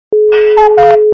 » Звуки » звуки для СМС » Кукушка как в часах - Ку-ку
При прослушивании Кукушка как в часах - Ку-ку качество понижено и присутствуют гудки.
Звук Кукушка как в часах - Ку-ку